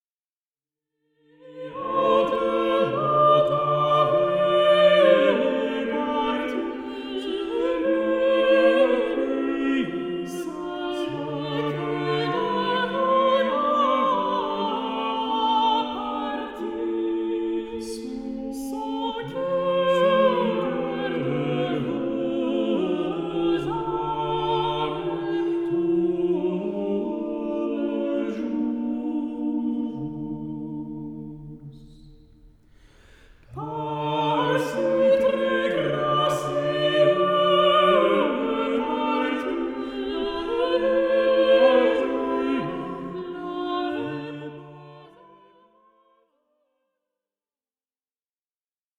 Polyphonies courtoises
harpe, vièle, luth, rebec et clavicythérium